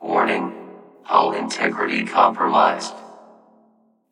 AI_Depth_Warning_3_OLD.ogg